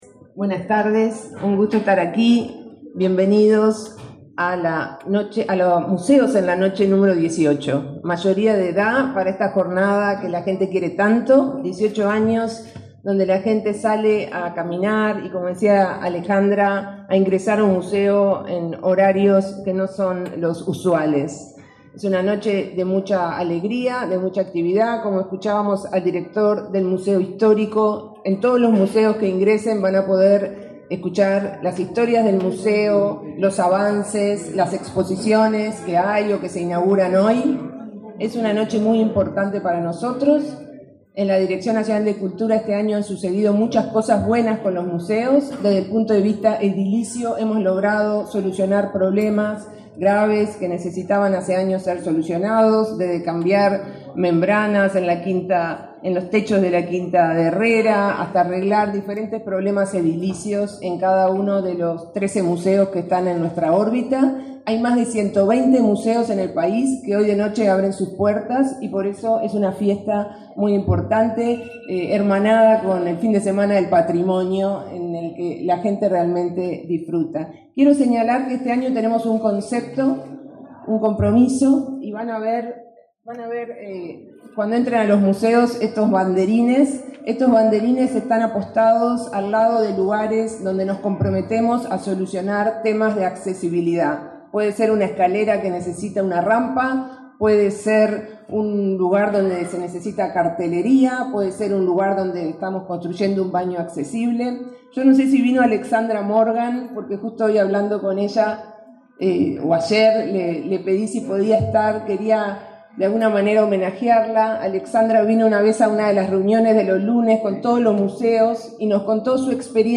Acto de lanzamiento de la edición 2023 de Museos en la Noche
Acto de lanzamiento de la edición 2023 de Museos en la Noche 08/12/2023 Compartir Facebook X Copiar enlace WhatsApp LinkedIn Este 8 de diciembre se realizó el acto de lanzamiento de la edición 2023 de Museos en la Noche, con la presencia del ministro de Educación y Cultura, Pablo da Silveira, y de la directora Nacional de Cultura, Mariana Wainstein.